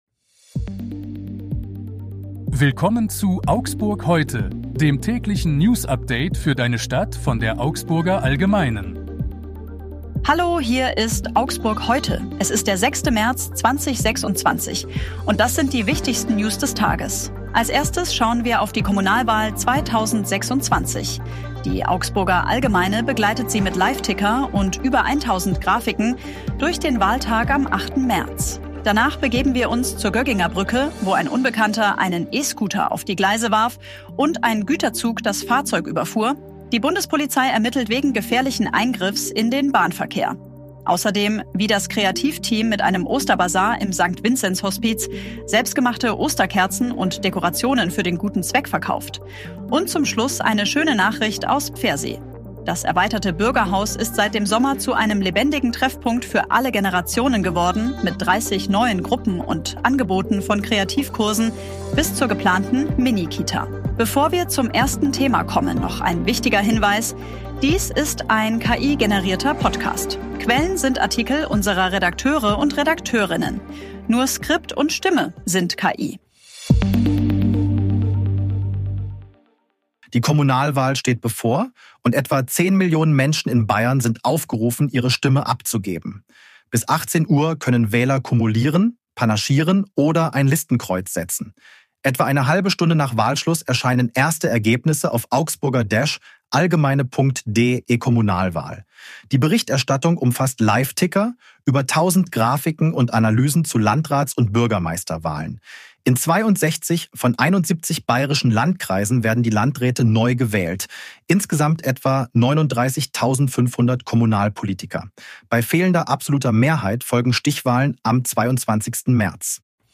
Die aktuellen Nachrichten aus Augsburg vom 06. März 2026.
Nur Skript und Stimme sind KI.